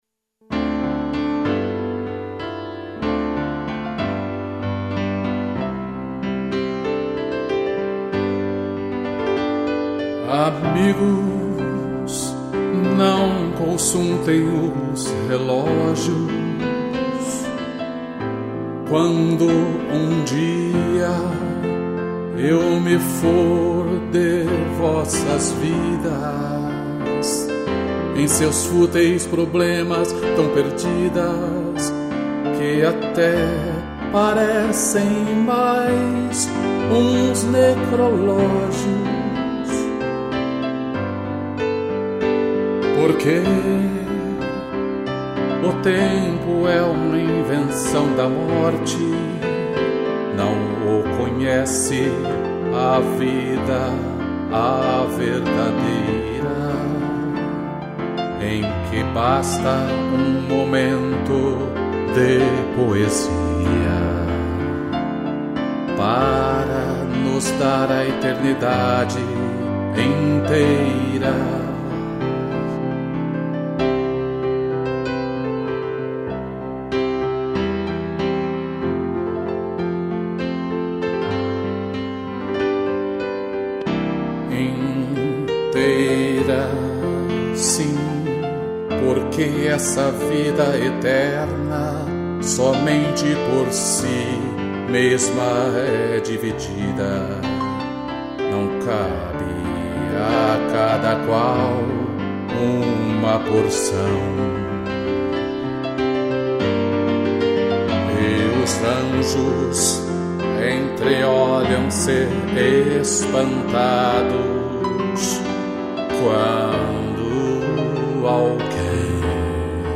2 pianos